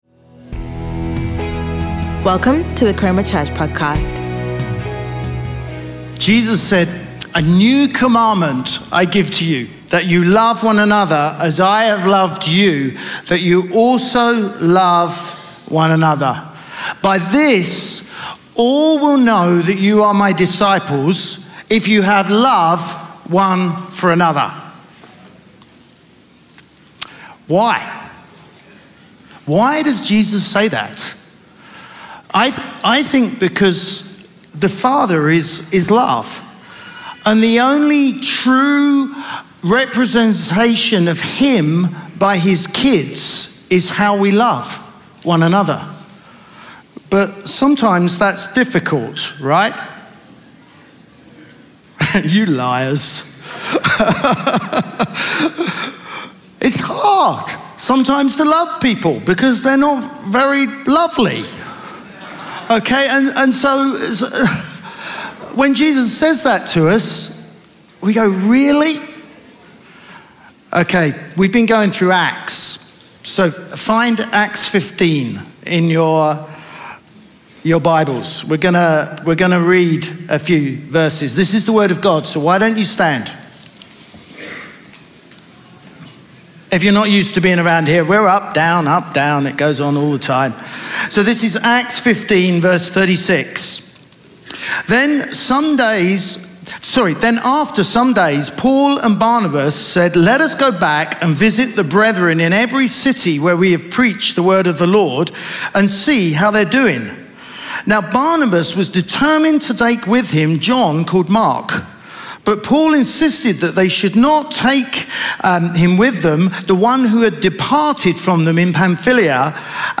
Sunday Sermon Fall In, Not Out